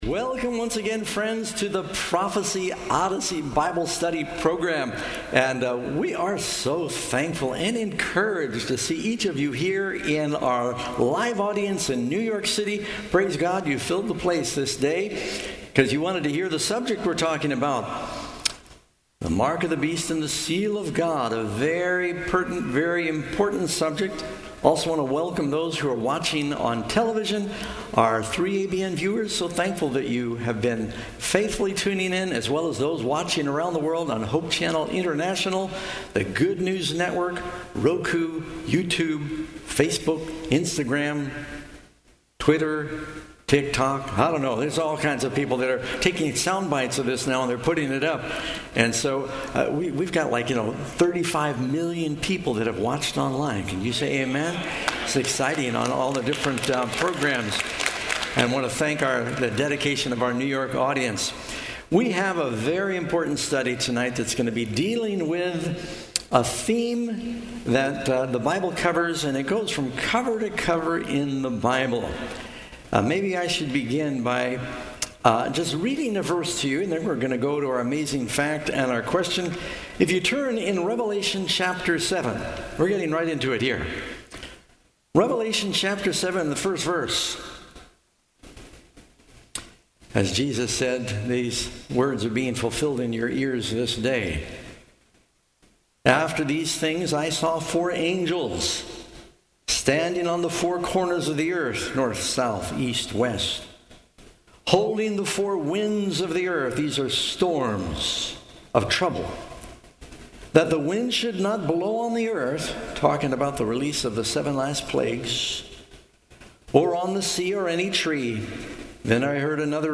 Seventh-day Adventist Church, Sutherlin Oregon
Pastor Doug Batchelor on 2024-10-05 - Sermons and Talks 2024